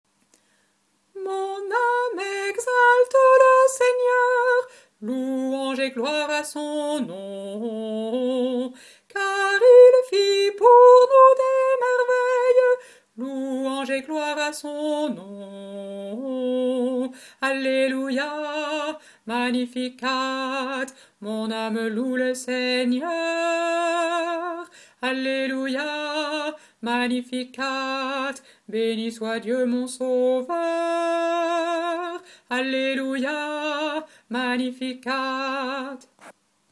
Voix chantée (MP3)COUPLET/REFRAIN
BASSE